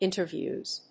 発音記号・読み方
/ˈɪntɝˌvjuz(米国英語), ˈɪntɜ:ˌvju:z(英国英語)/